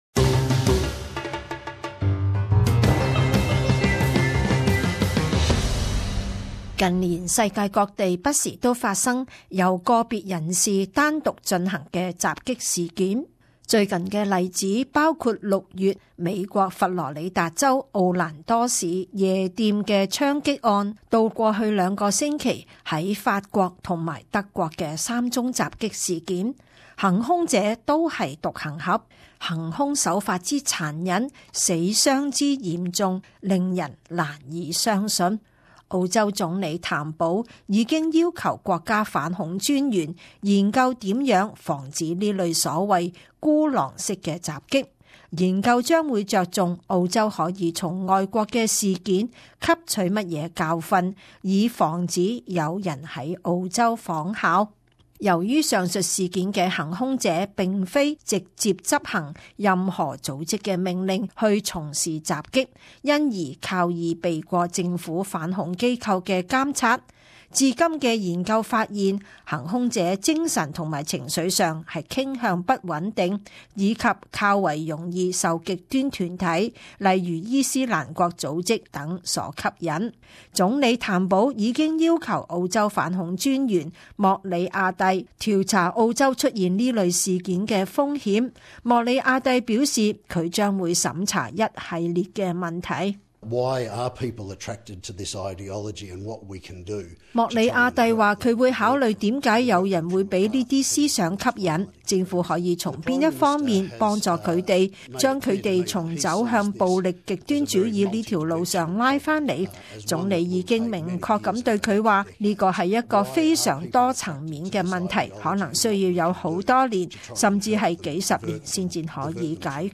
【時事報導】澳洲如何面對孤狼恐襲